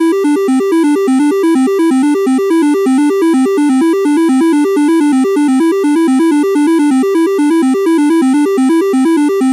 Slot-Reel-Spin-Melody.wav